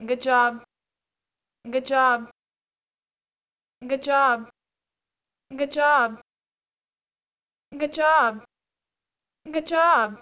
sample stimuli: five levels of positivity